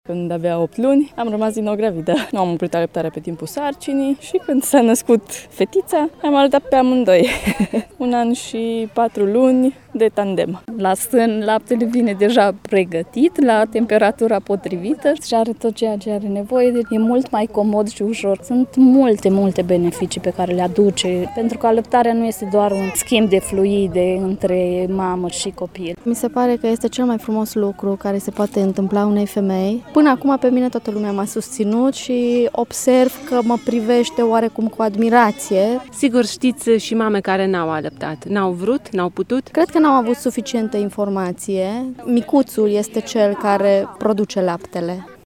Manifestărilor de la Tîrgu Mureș li s-au alăturat zeci de mame dornice să împărtășească din experiența personală privind alăptarea: